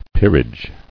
[peer·age]